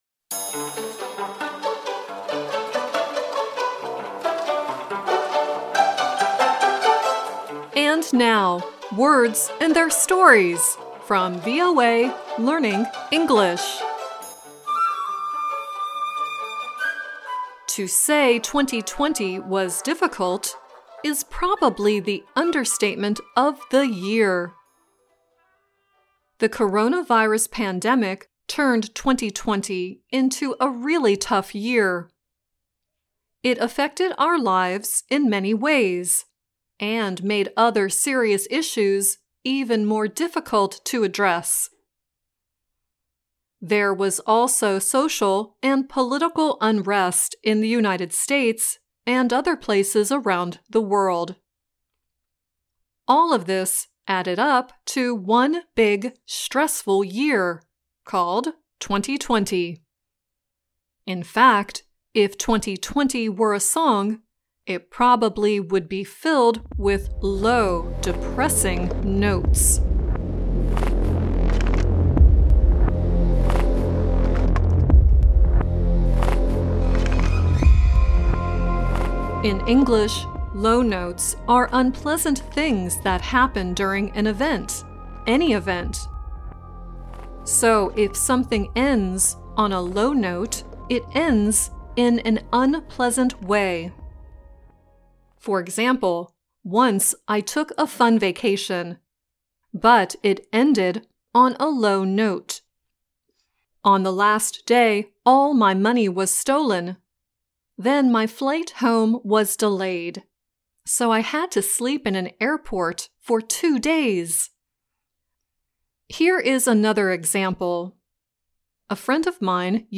The first song is the production music "Blurred Horizon.